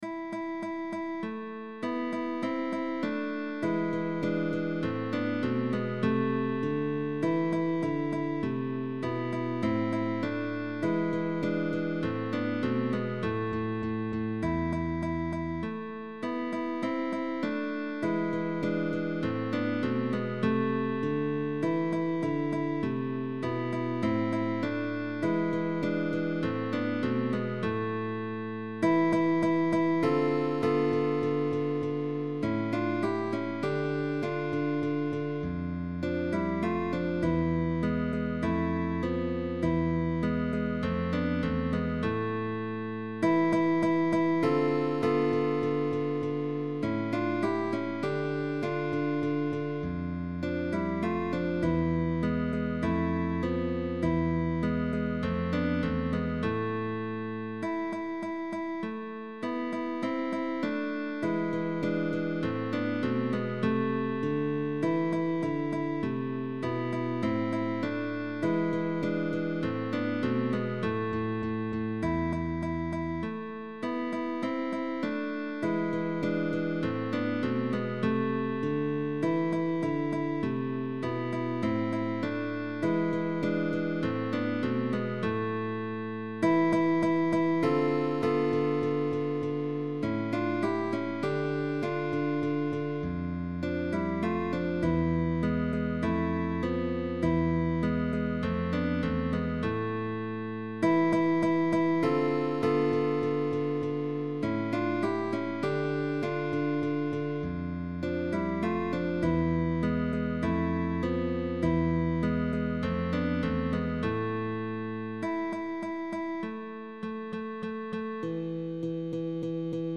TRÍO DE GUITARRAS
Canción popular española adaptada para trío de guitarra